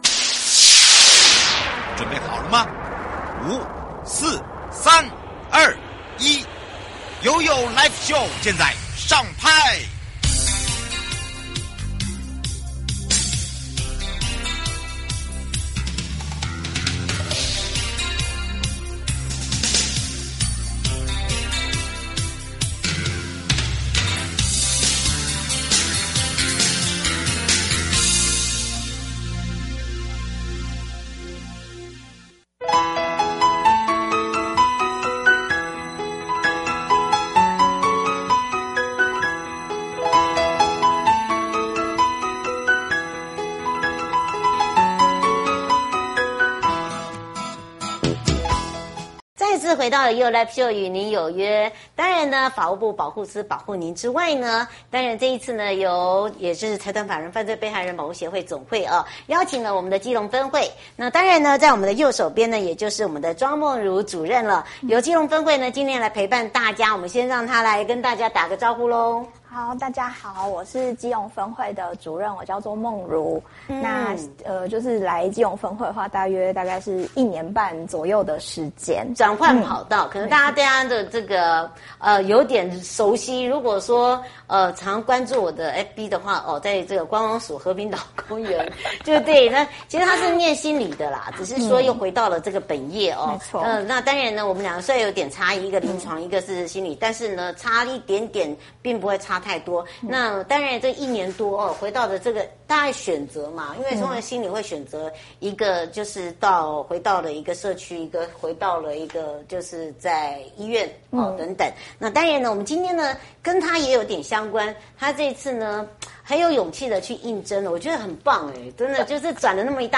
受訪者： 直播